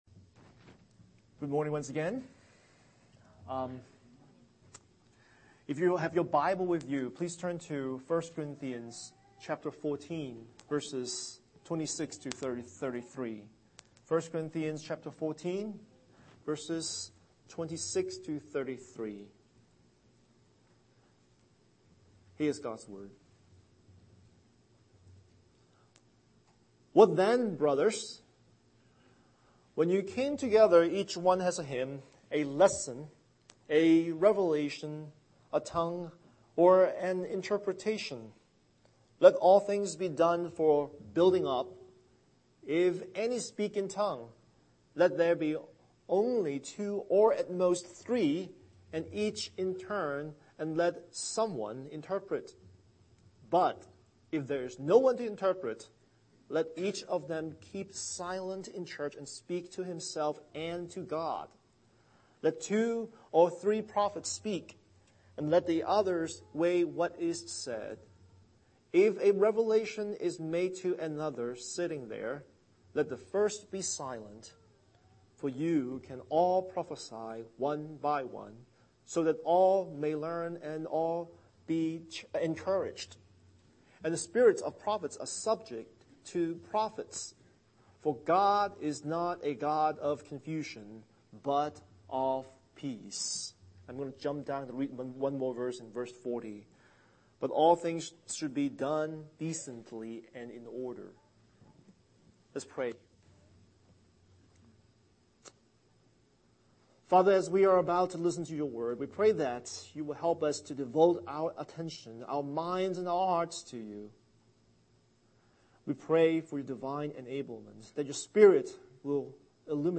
Series: Sunday Worship